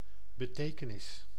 Ääntäminen
IPA: /bə.ˈteː.kə.nɪs/